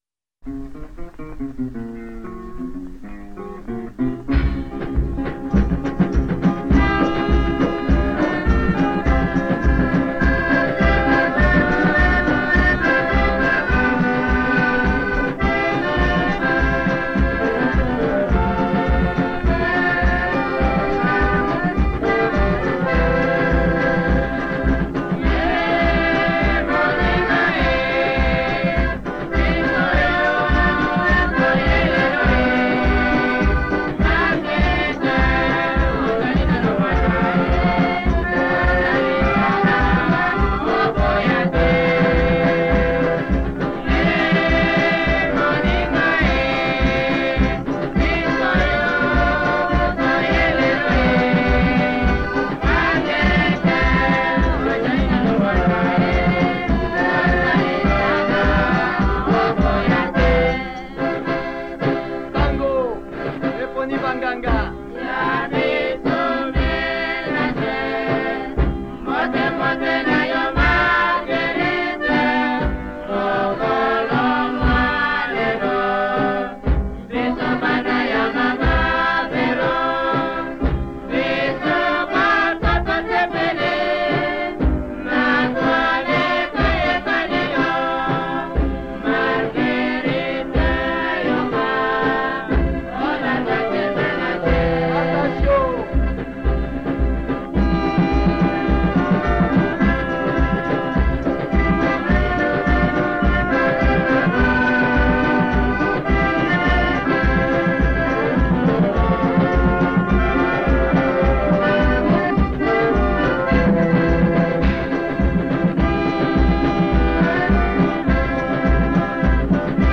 la etiquetaron como polka piké.